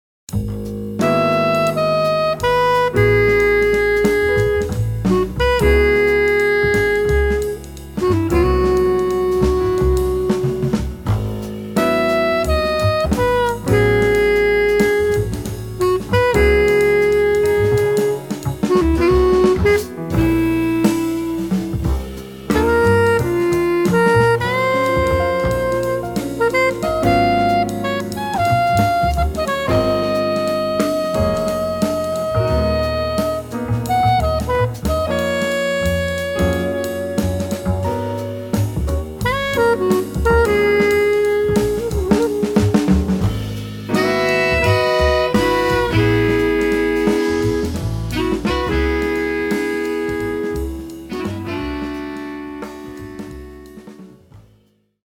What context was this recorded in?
at Skyline Production, South Orange, NJ